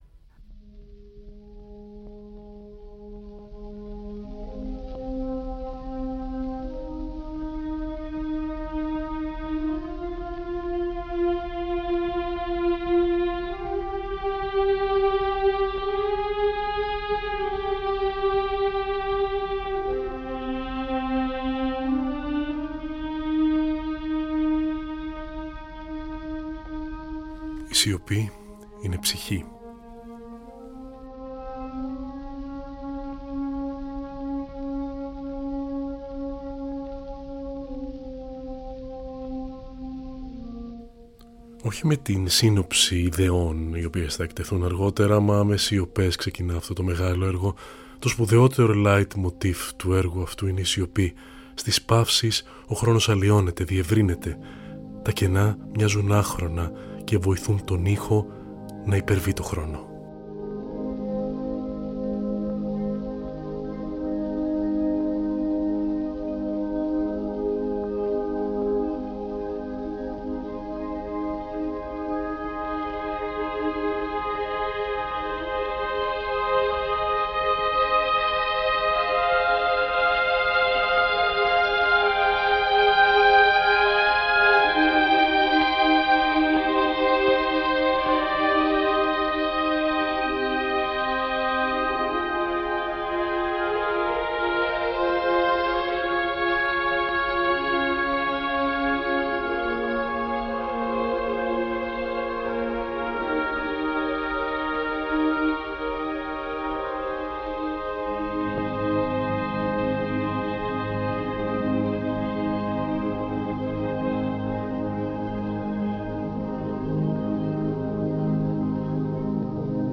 Στην εκπομπή της Πέμπτης 31.10.2024 ακούμε Harrison Birtwhistle, Richard Wagner, Ludwig van Beethoven...